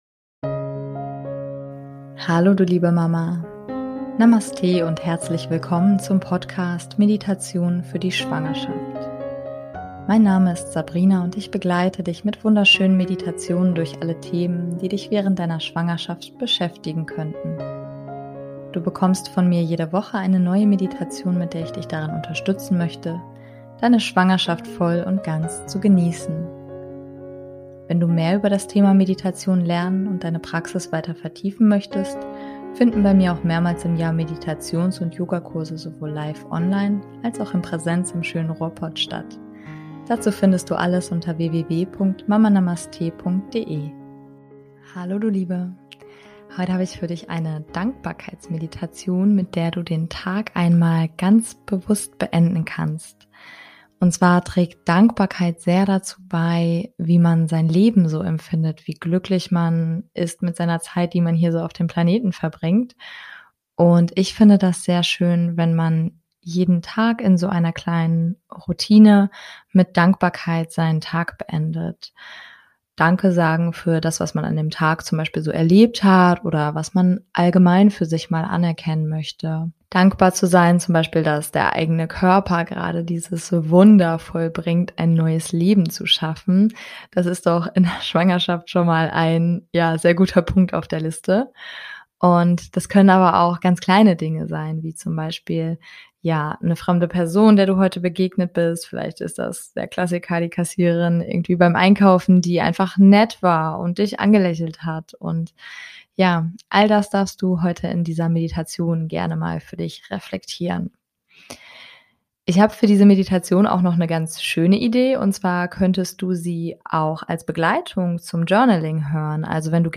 Falls du dich lieber mehr fallen lassen möchtest und gar einschlafen magst nach der Meditation: Ich lasse diese Meditation mit ein wenig Musik begleitet auslaufen ohne dich zurückzuholen.